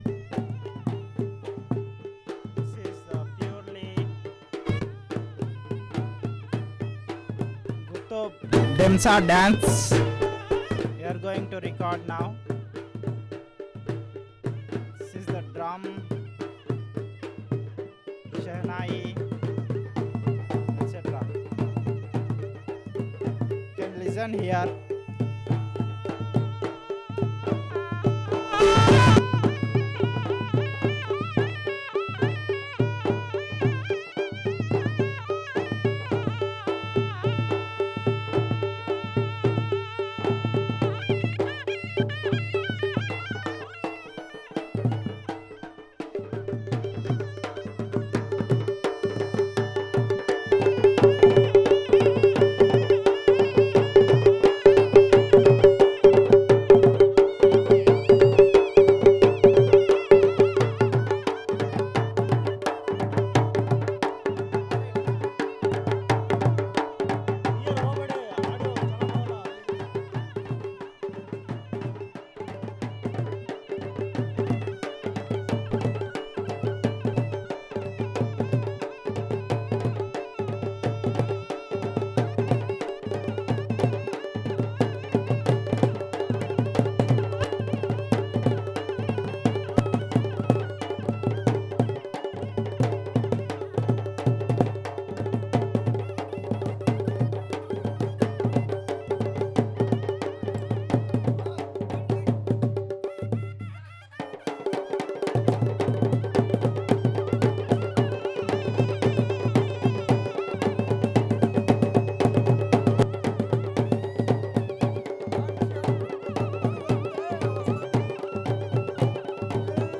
Perfomance of music during Demsa dance